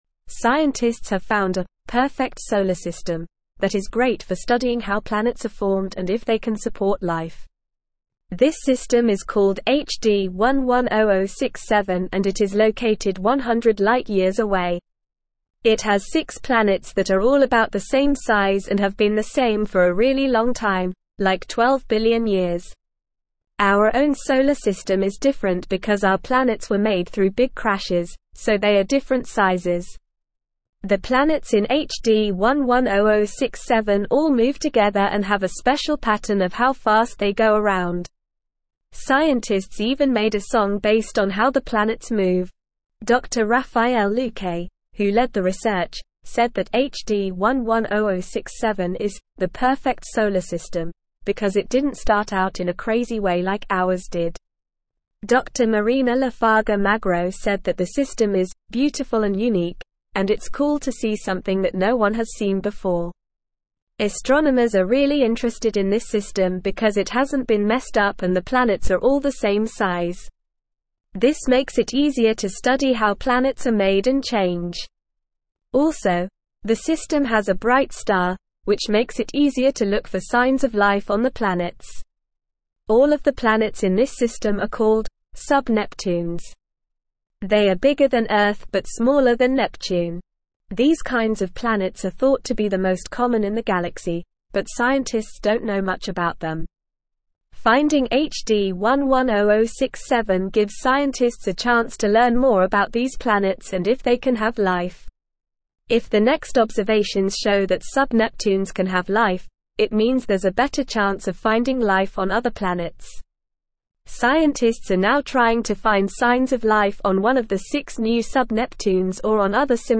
Normal
English-Newsroom-Upper-Intermediate-NORMAL-Reading-Perfect-Solar-System-Potential-for-Life-and-Discovery.mp3